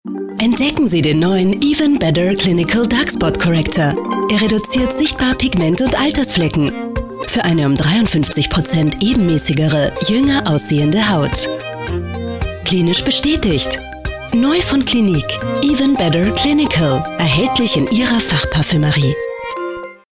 stimmprobe werbesprecherin . sprecher werbung . werbespots . tv spots . radiospots
Clinique Even Better Clinical/TV-spot AT/mp3